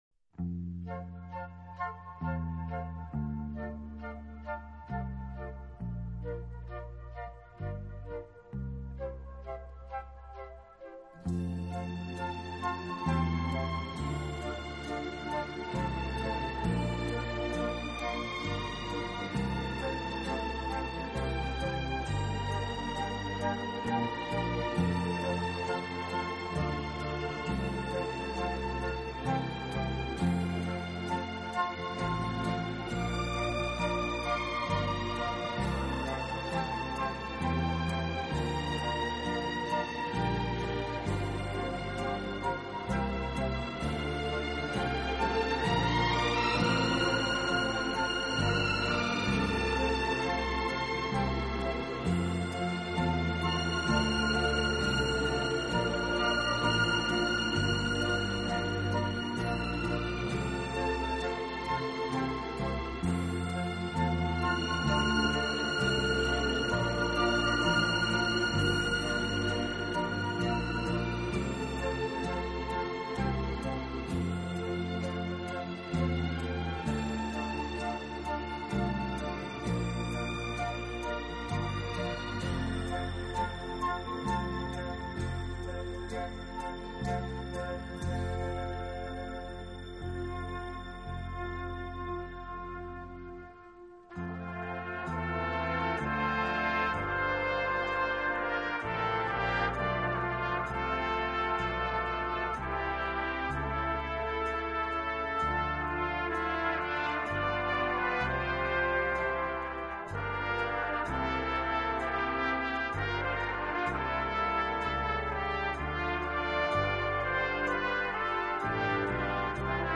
轻音乐